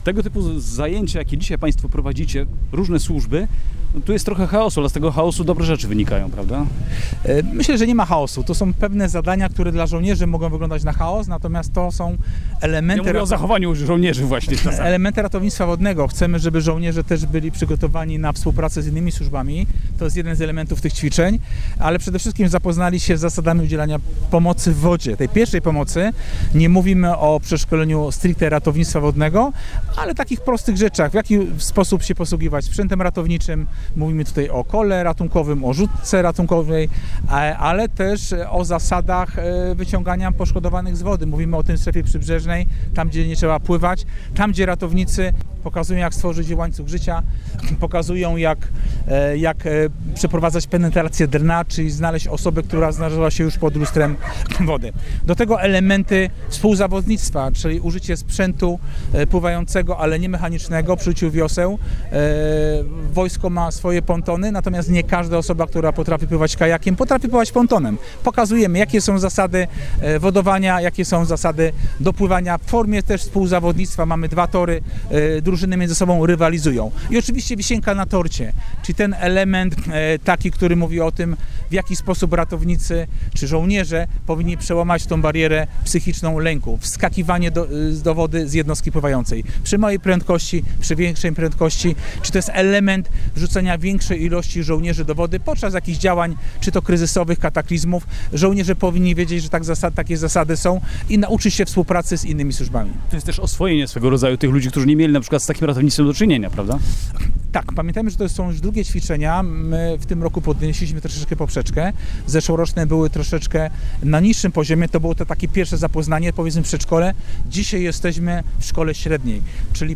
Więcej na temat wspólnych, sobotnich ćwiczeń służb w Borzygniewie można się dowiedzieć z wypowiedzi ich uczestników.